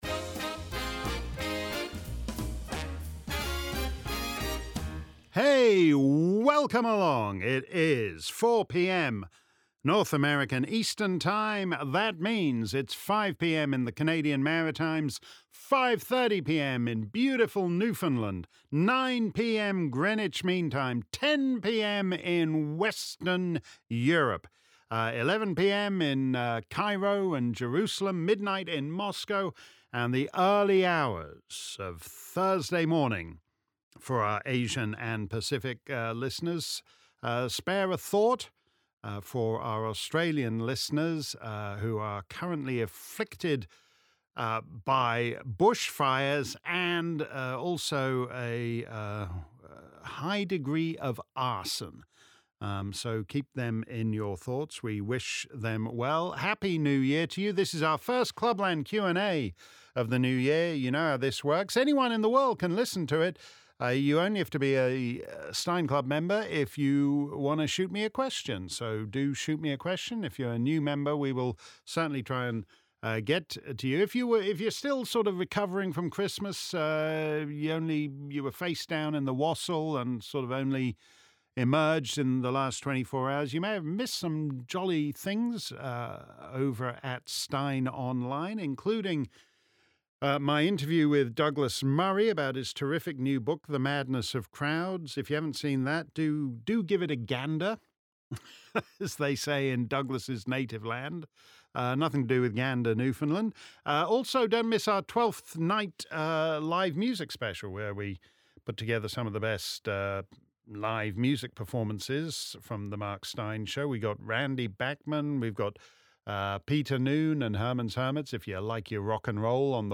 If you missed our livestream Clubland Q&A on Wednesday afternoon, here's the action replay. Simply click above and settle back for an hour-plus of my answers to questions from Mark Steyn Club members around the planet.